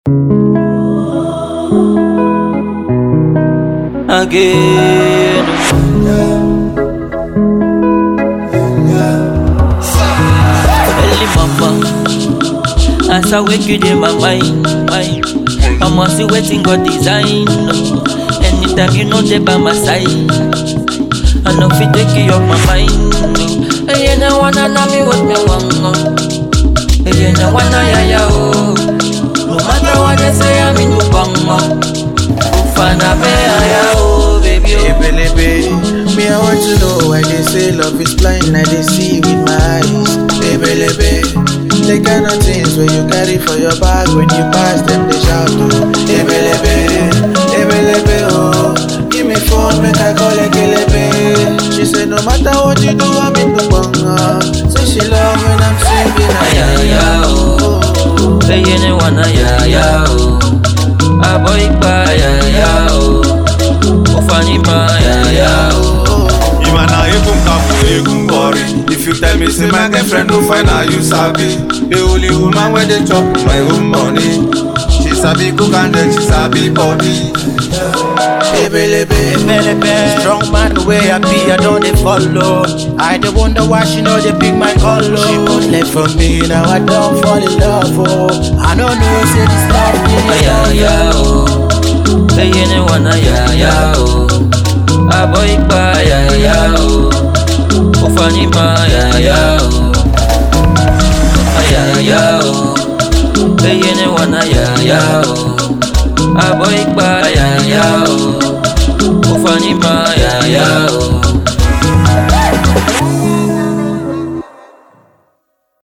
Song genre: AFRO FUSION